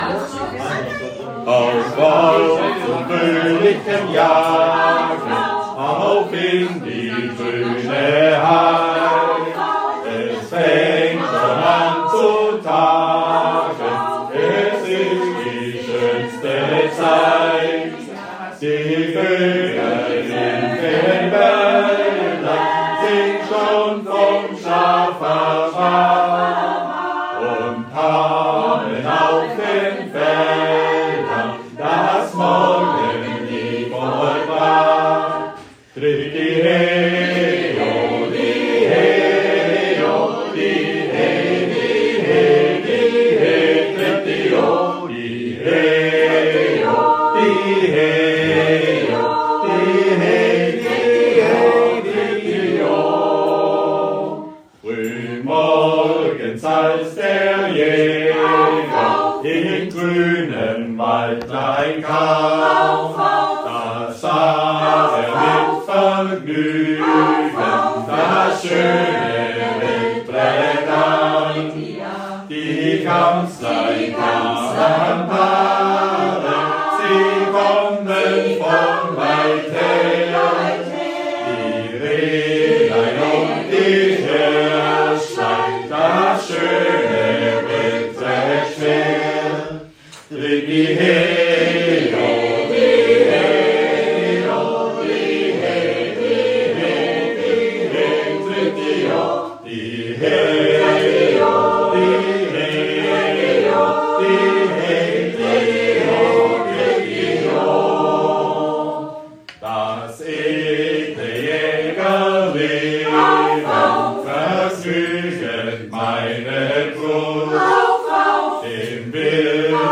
Männerchor)mp3png
live